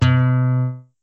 Original 4i Sound Processor guitar sample, extracted from PDP-11 floppy disk.